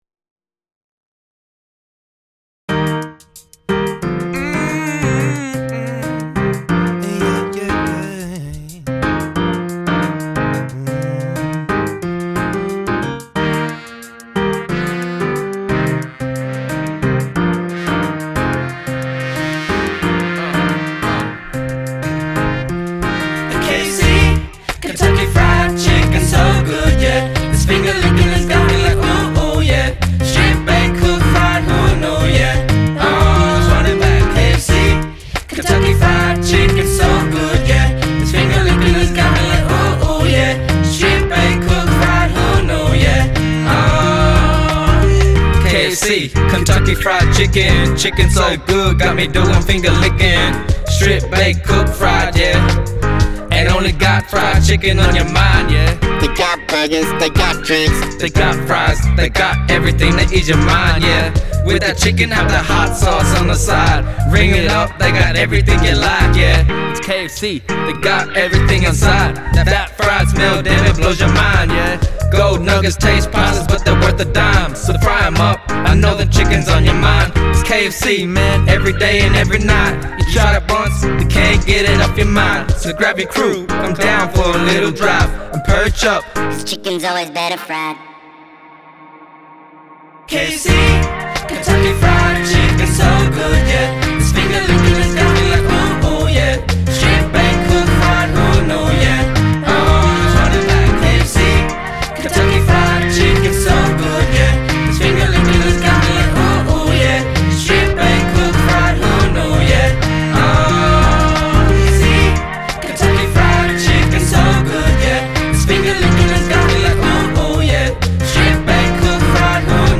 Group of young people singing into a microphone in a b & w ohoto
The journey culminated in four professionally recorded tracks, which were presented alongside corresponding artworks at the Place of Plenty Showcase.